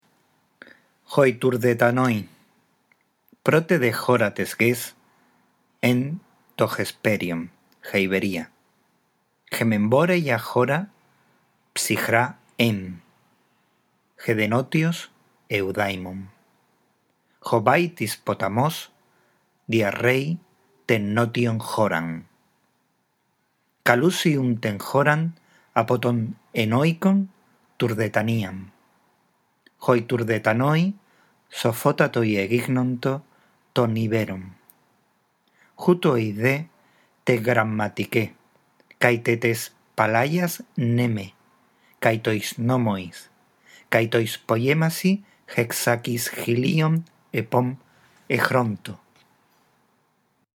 La audición de este archivo te ayudará en la práctica de la lectura en voz alta